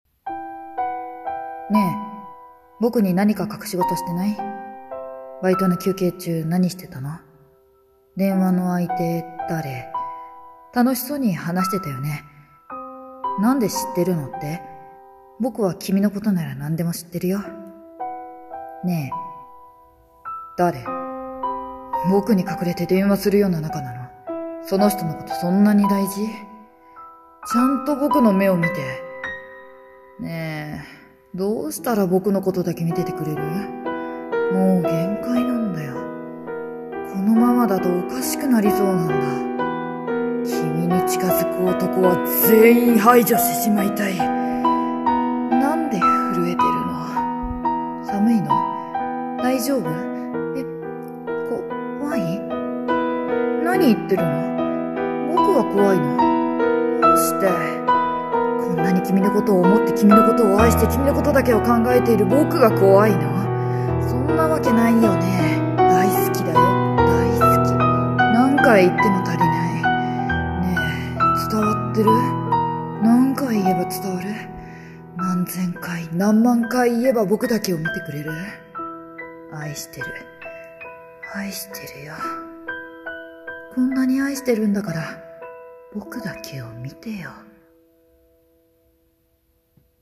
【声劇台本】僕の愛 伝わってる？